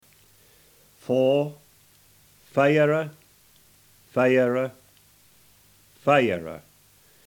Puhoi Egerländer Dialect